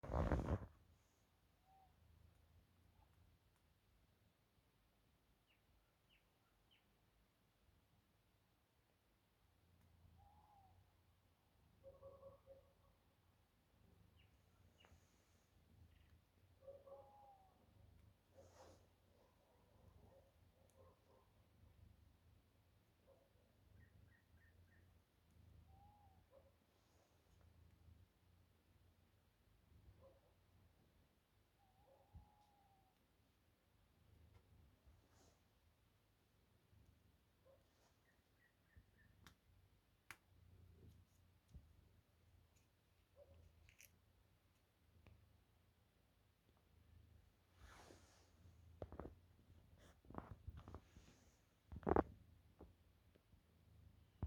Птицы -> Совообразные ->
серая неясыть, Strix aluco
СтатусПоёт
Примечанияkāda pūce un, šķiet, lakstīgala?